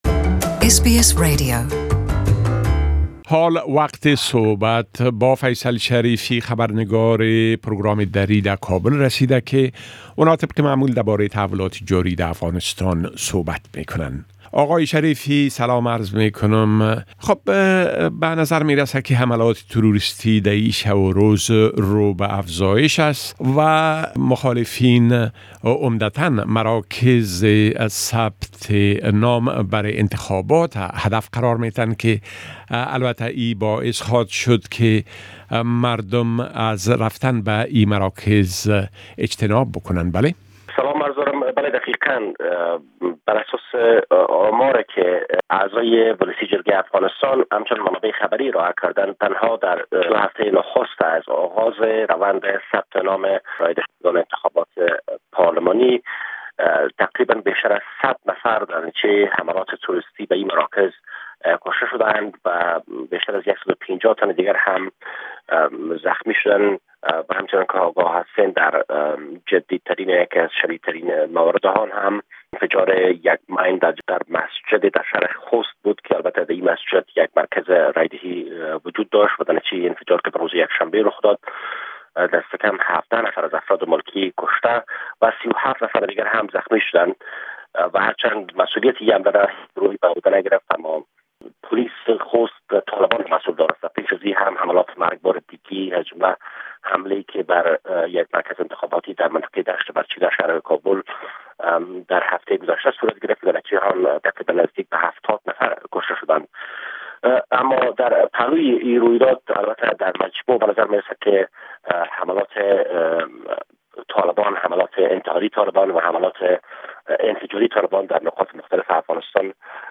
Report from Afghanistan 8 May 2018
A report from our correspondent in Afghanistan